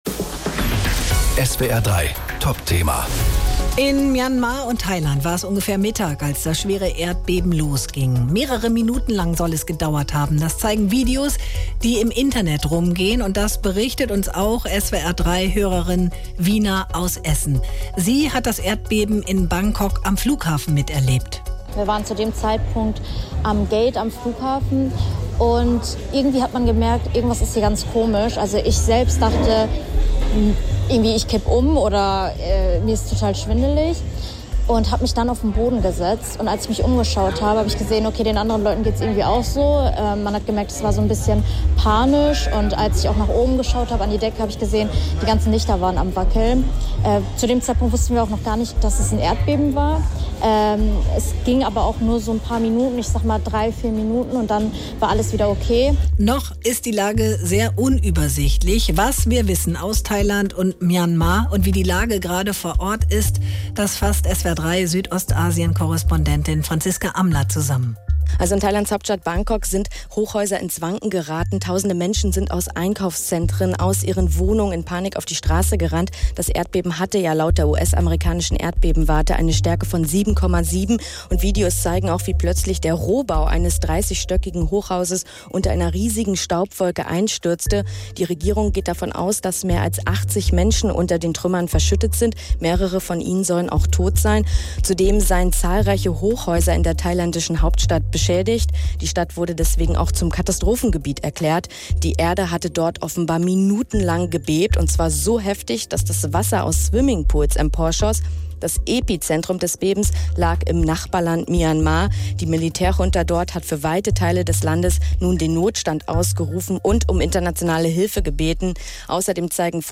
Nachrichten „Ich wusste erst gar nicht, dass das ein Erdbeben war“